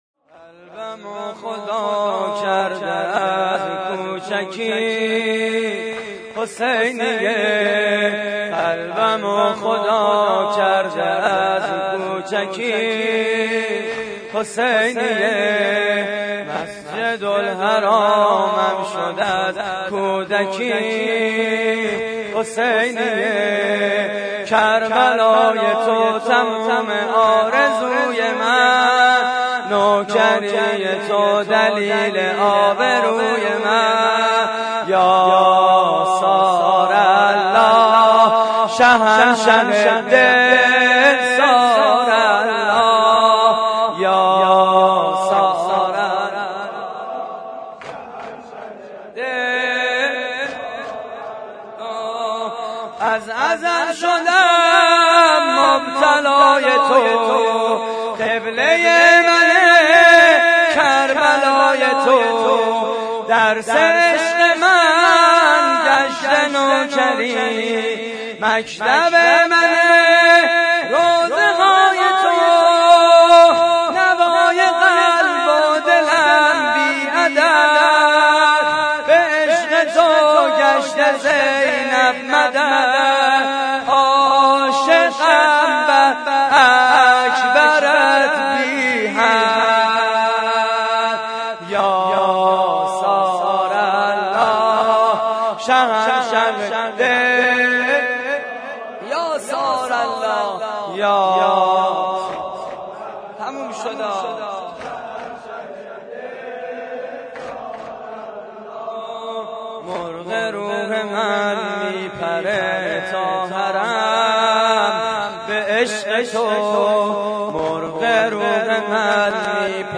واحد: کربلای تو تموم آرزوی من
مراسم عزاداری شب هفتم ماه محرم